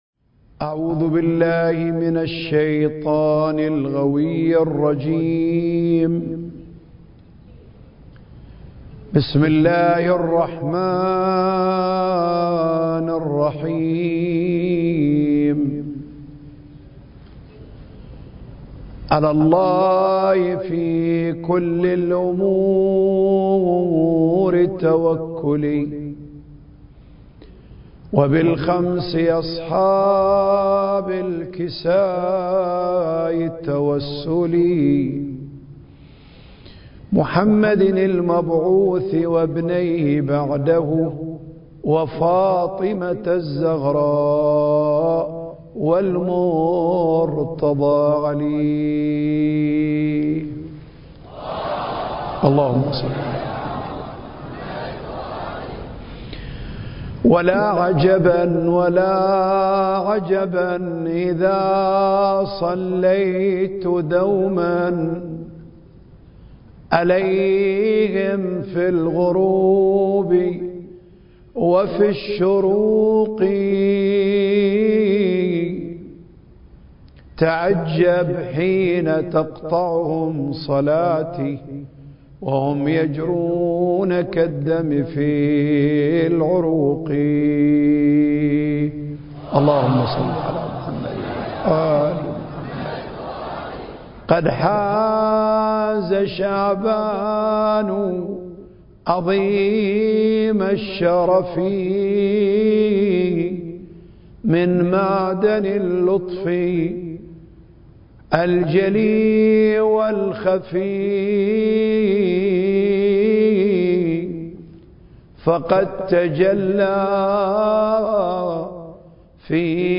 المكان: الأوقاف الجعفرية بالشارقة التاريخ: 2020